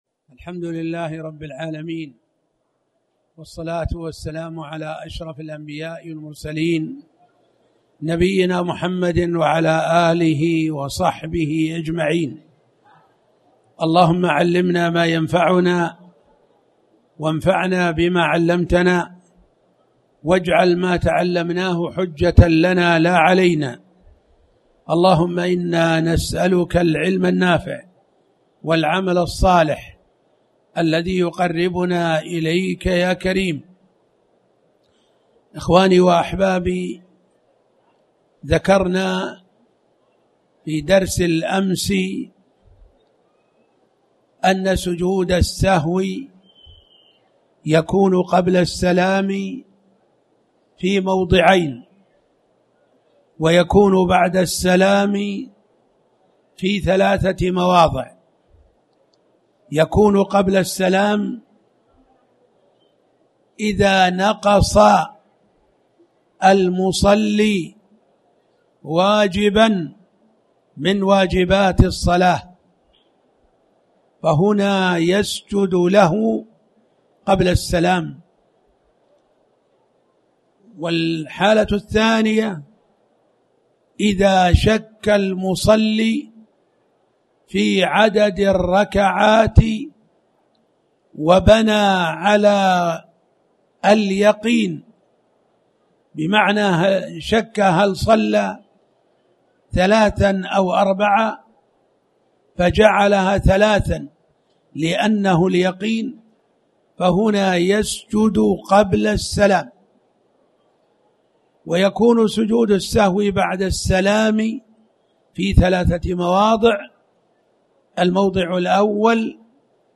تاريخ النشر ٦ محرم ١٤٣٩ هـ المكان: المسجد الحرام الشيخ